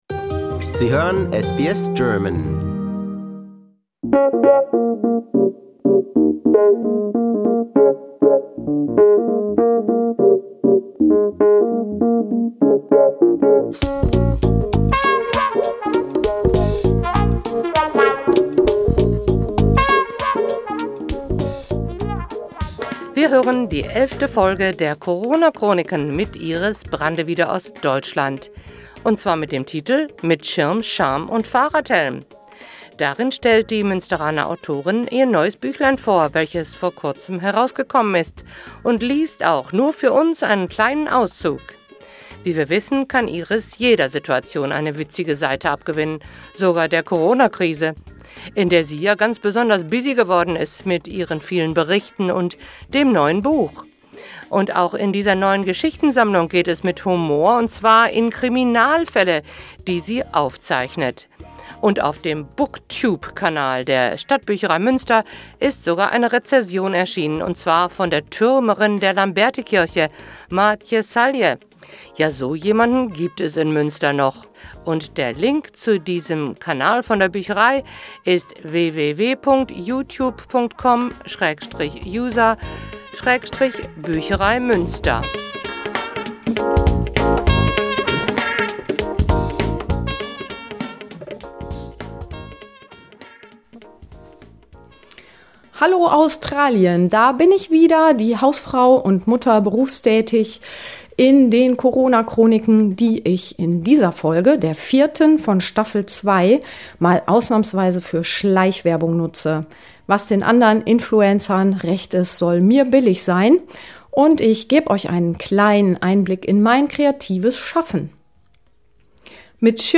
Darin stellt die Münsteraner Autorin ihr neues Büchlein vor, welches vor Kurzem herausgekommen ist, und liest auch nur für uns, einen kleinen Auszug.